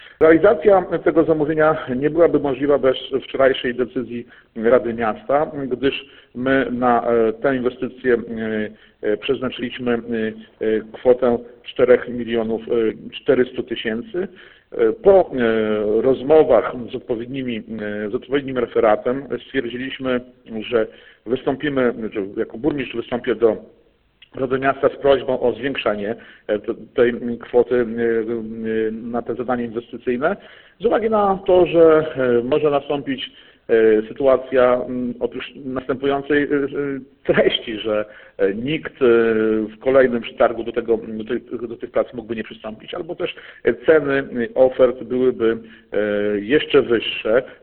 O szczegółach mówi Dariusz Latarowski, burmistrz Grajewa.